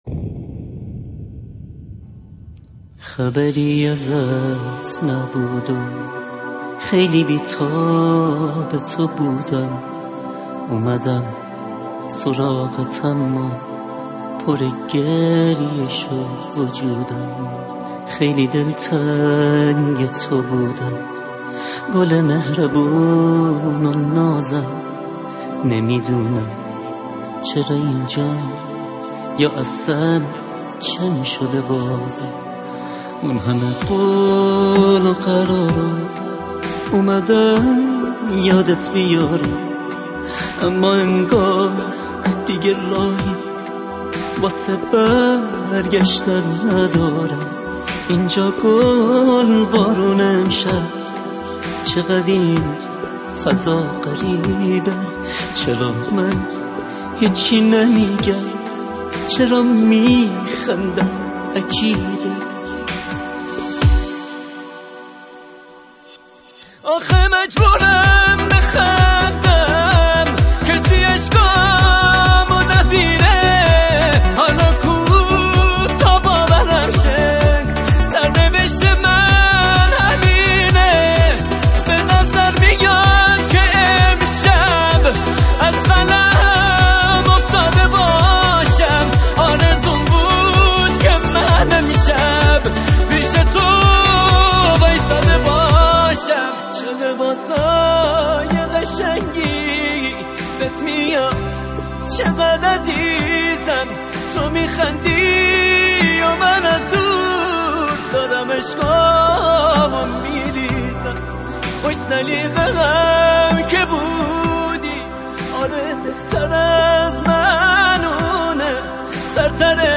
صداش عشق آرامش
واقعان درود به تو که انقدر سوزناک میخونی .